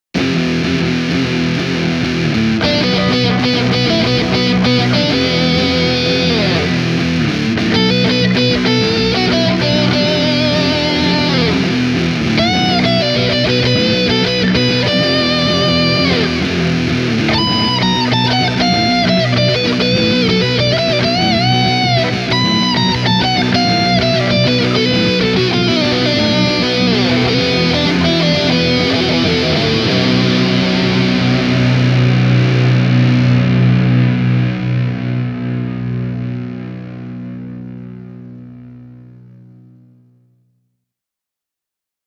ESP:n LTD SCT-607B suorastaan rakastaa rankkaa riffailua ja kaikenlaista kieliakrobatiaa.
Pitkän mensuurin ja aktiivimikityksen tuoma selkeys helpottaa huomattavasti myös hyvin rankkojen särösoundien päällekkäin kasaamista, ilman että kokonaiskuva puurottaisi ongelmallisesti.